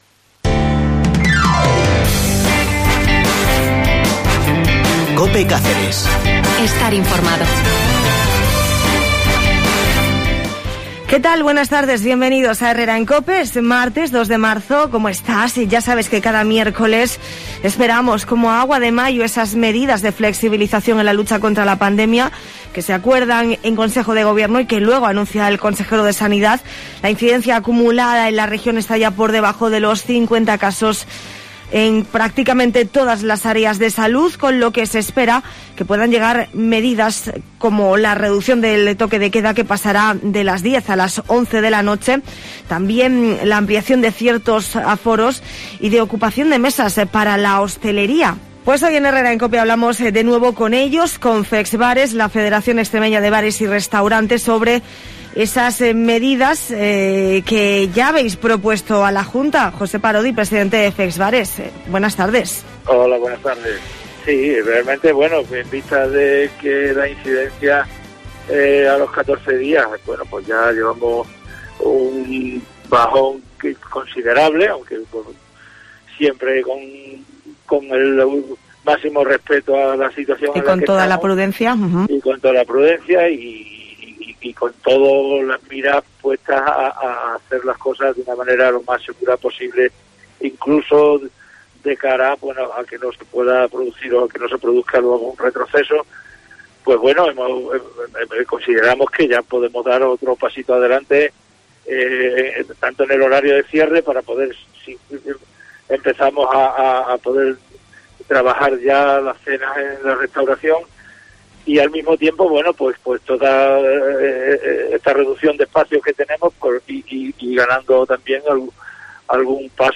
ha pasado hoy por los micrófonos de Herrera en Cope Cáceres donde ha destacado que espera que con los datos de la incidencia acumulada en la región se puedan flexibilizar las condiciones en las que desarrollan su trabajo cada día.